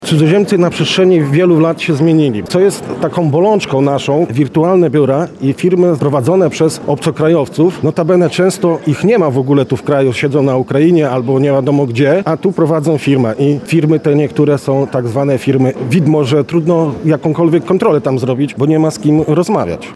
O prawie pracy i cudzoziemcach zatrudnianych w Polsce rozmawiali w piątek (03.10) inspektorzy pracy, przedsiębiorcy i przedstawiciele służb mundurowych podczas konferencji w Lubelskim Urzędzie Wojewódzkim.